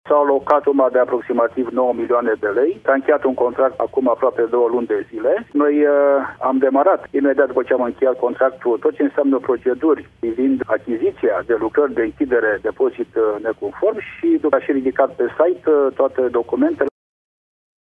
Fondurile sunt asigurate de Administrația Fondului de Mediu, spune Virgil Popa, primarul din Săcele:
primar-sacele.mp3